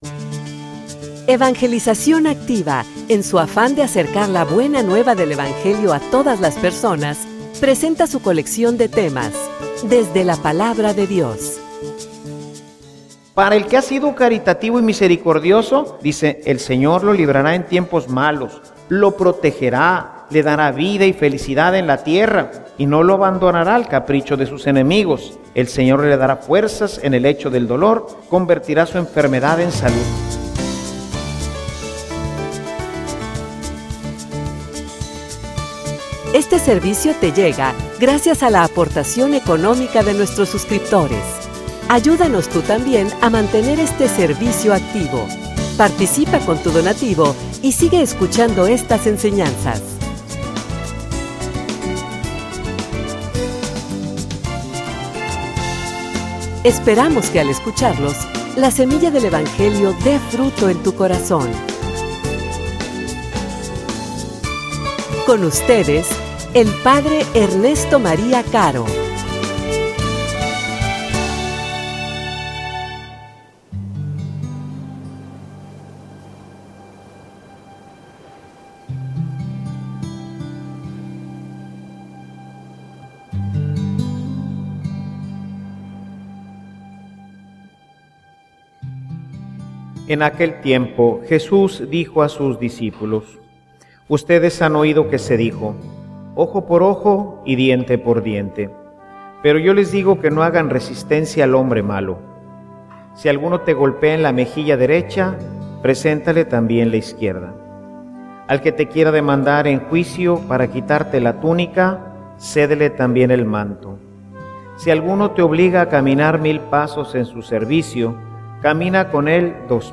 homilia_El_amor_llevado_al_extremo_la_misericordia.mp3